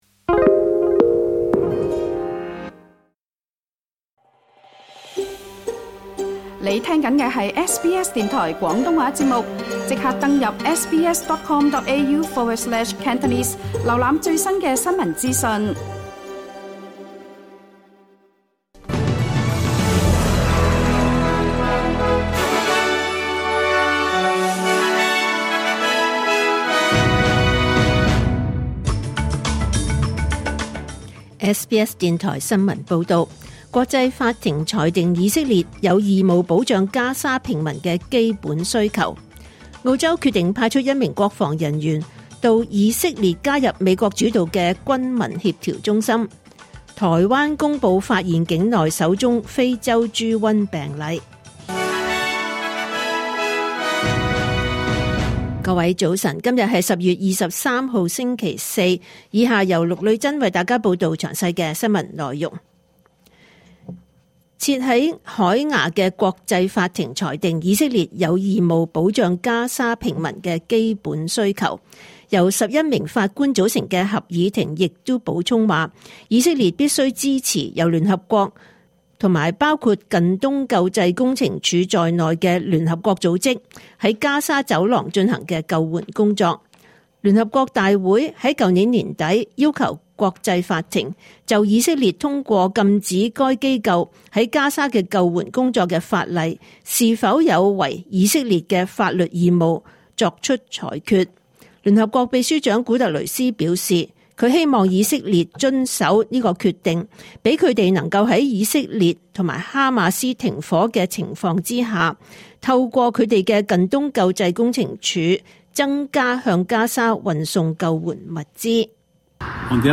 2025 年 10 月 23 日 SBS 廣東話節目九點半新聞報道。